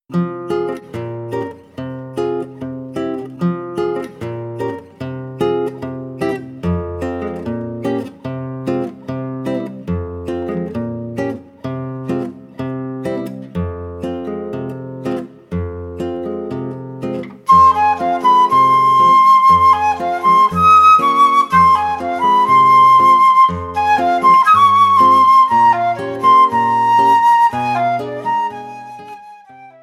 flute
6-string guitar